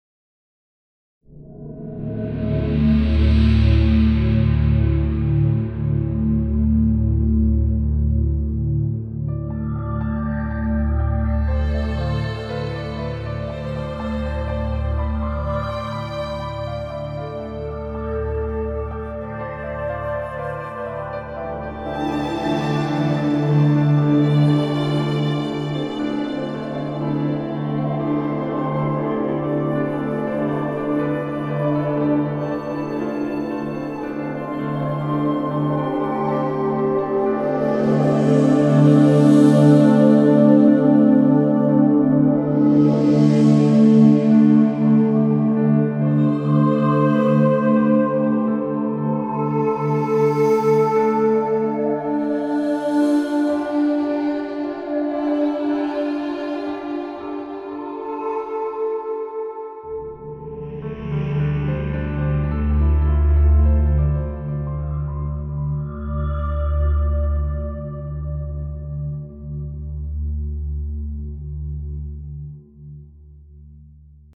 Ein Bespiel für die sehr schöne Hintergrundmusik des Spiels.